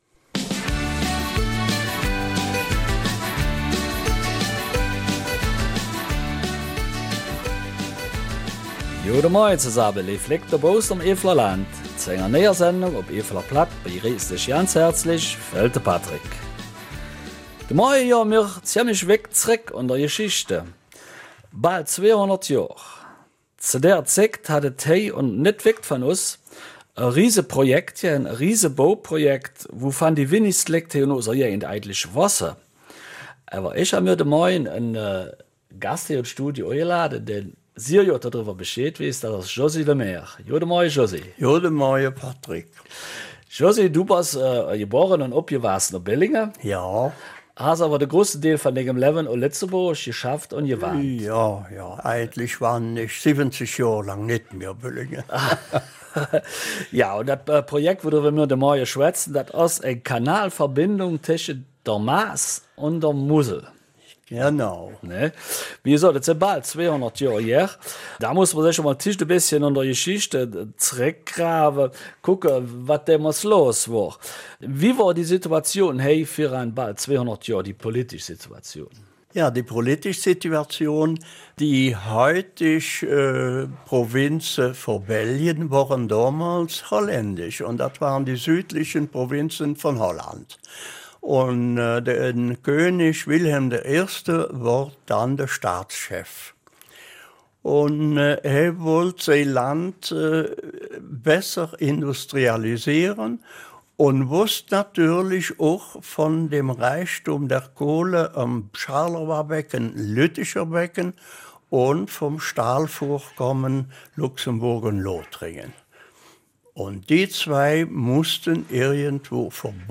Eifeler Mundart: Bauprojekt ''Verbindung Maas und Mosel''